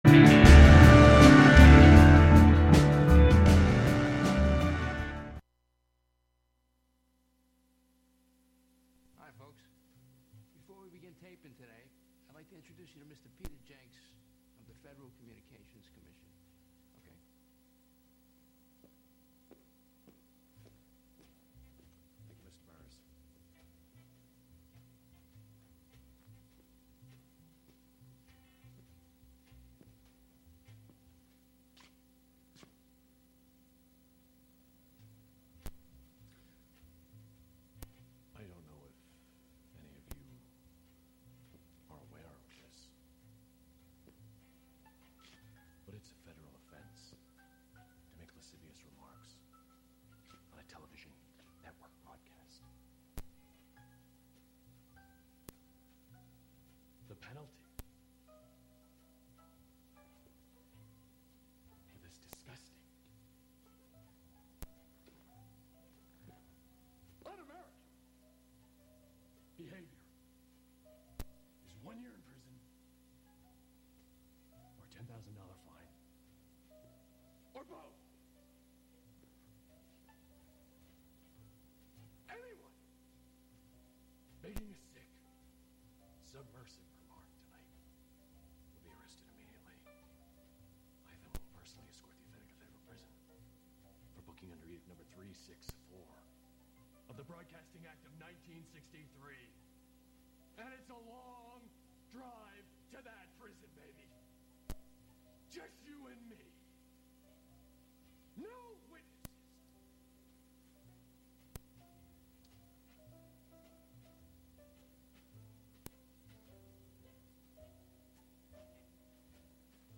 soul/R&B, hip hop, jazz, spoken word, choral music